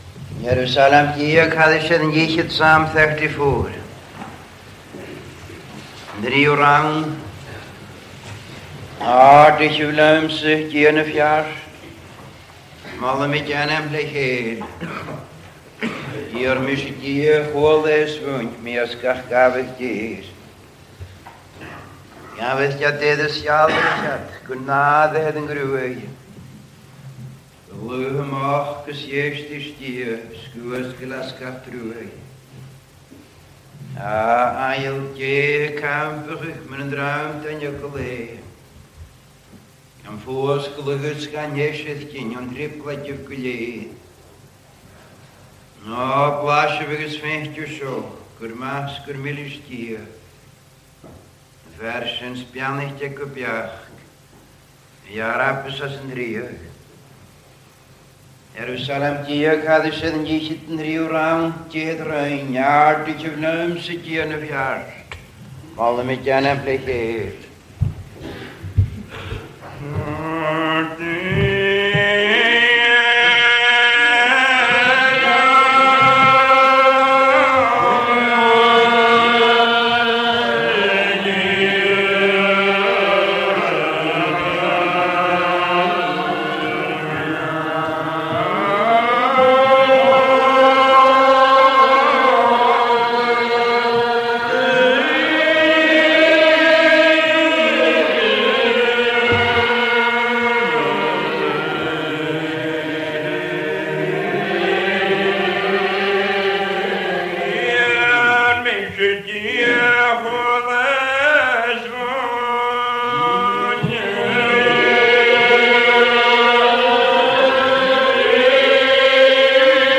Most, if not all, of these sermons were recorded in the past as part of our Tape Ministry and quite a few have been lovingly retained by some of the older members of our congregation.
We made the decision to record the entire services including the singing and prayers.